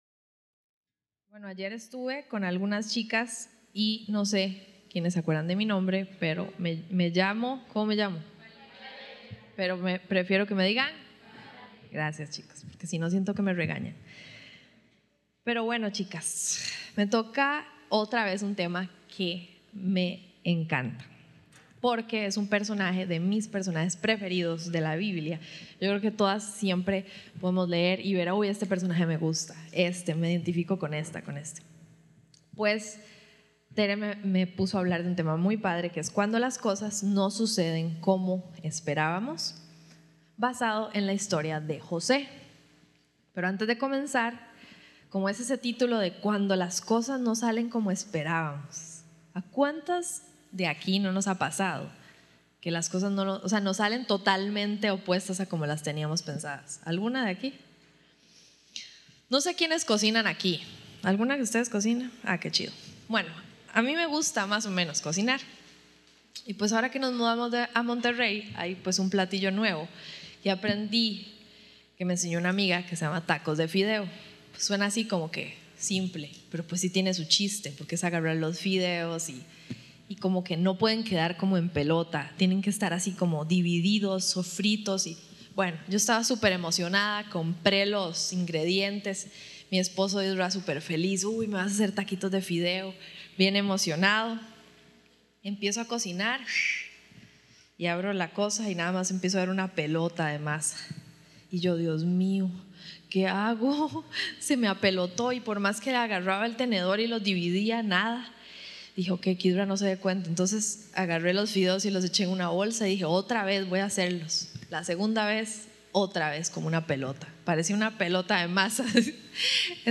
Retiro de Jovencitas Passage: Génesis 39:1-6